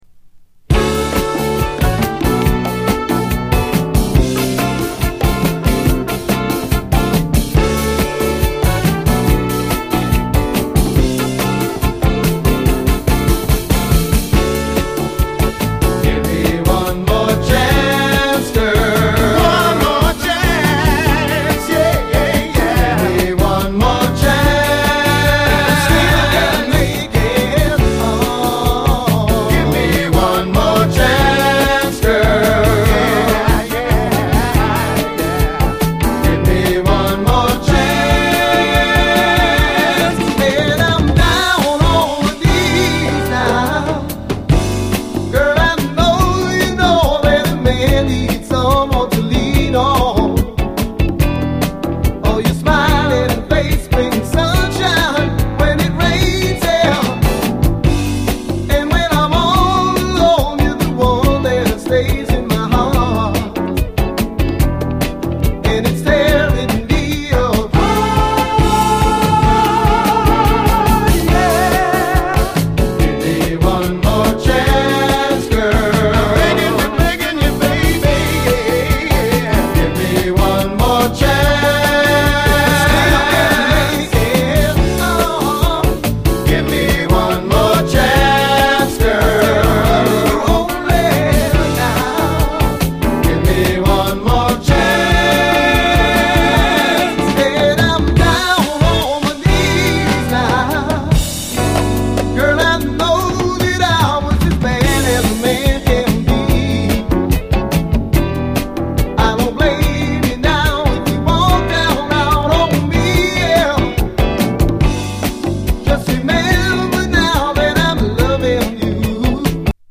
チカーノ・ミュージック
底抜けにゴキゲンな最高ハッピー・ズンドコ・ダンサー
このチープでDIYなバウンシー感、酸いも甘いも孕んだ温もりに痺れます！
当然メロウ＆スウィートなバラードもあり